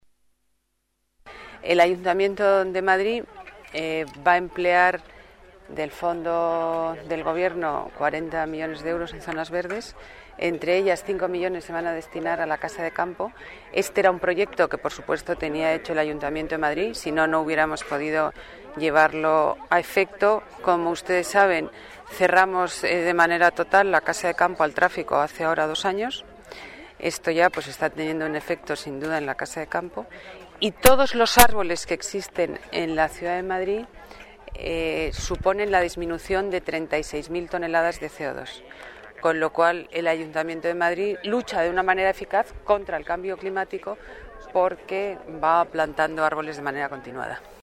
Nueva ventana:Declaraciones de la delegada de Medio Ambiente, Ana Botella, durante la plantación en Casa de Campo